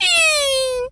pgs/Assets/Audio/Animal_Impersonations/mouse_emote_08.wav at master
mouse_emote_08.wav